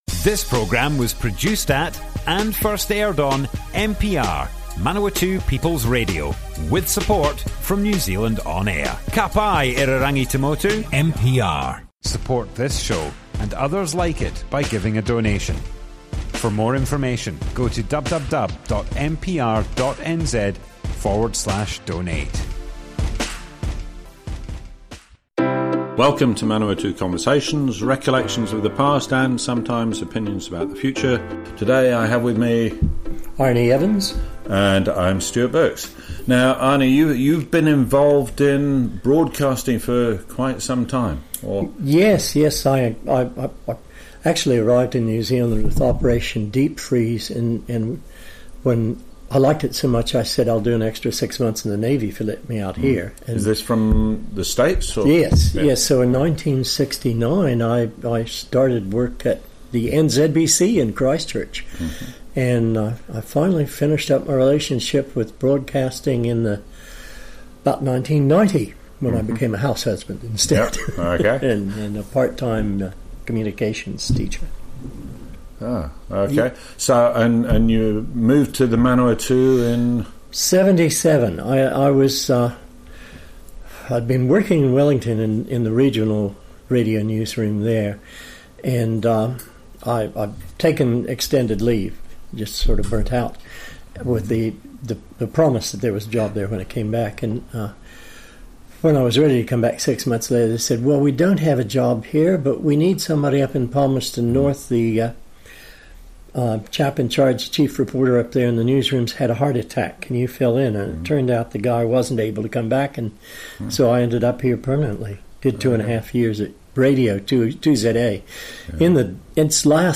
Manawatu Conversations More Info → Description Broadcast on Manawatu People's Radio, 3rd March 2020.
oral history